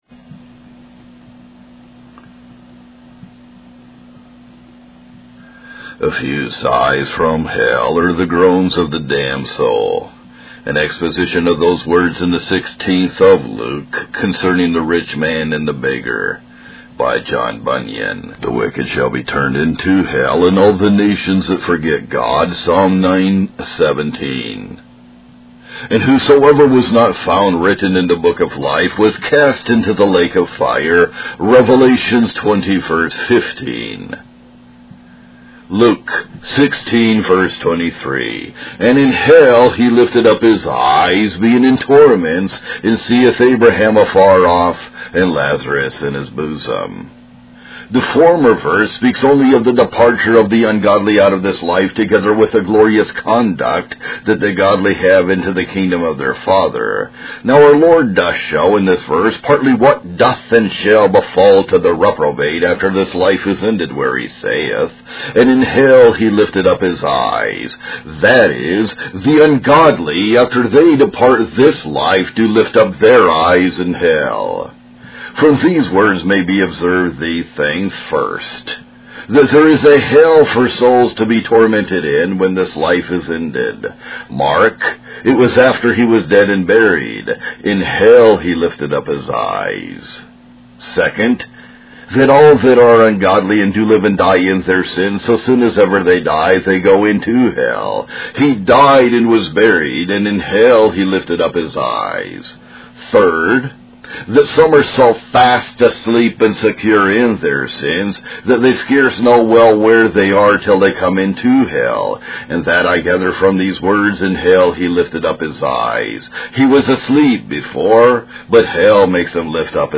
A Few Sighs From Hell (Reading) by John Bunyan | SermonIndex